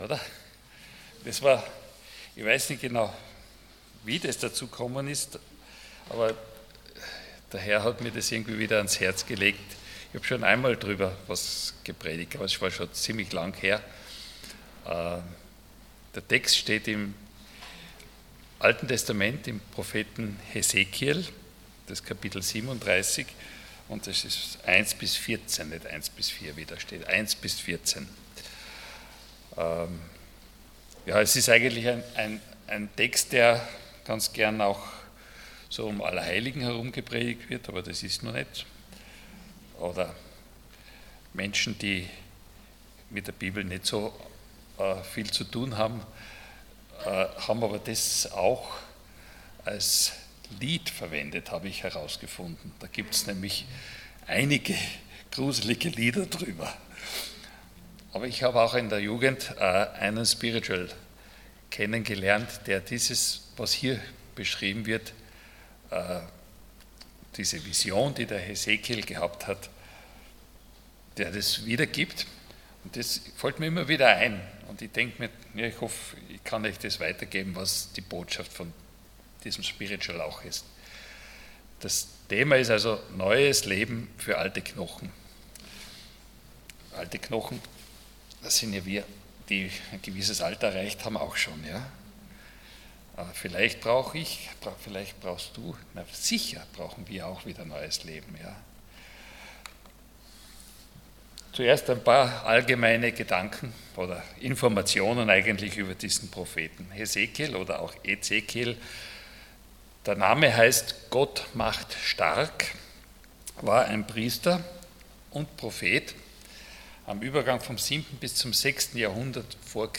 Allgemeine Predigten Passage: Hesekiel 37,1-14 Dienstart: Sonntag Morgen Neues Leben für alte Knochen « Lebst du noch oder lobst du schon?